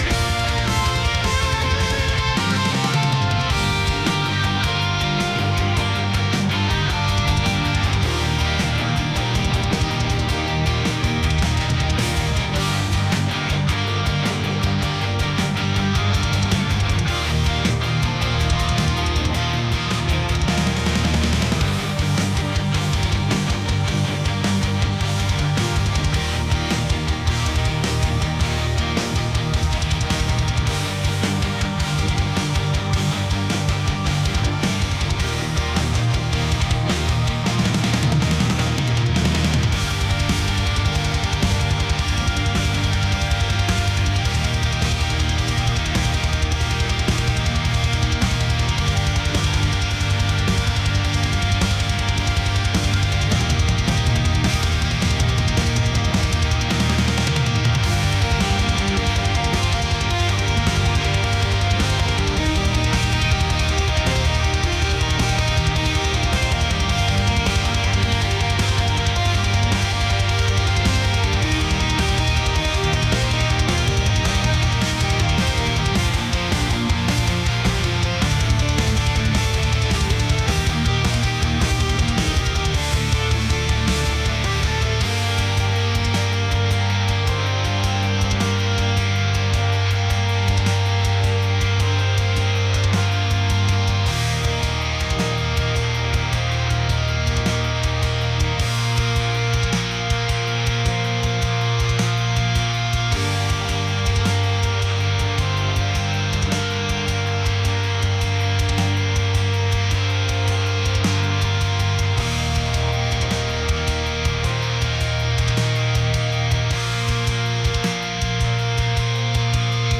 heavy | metal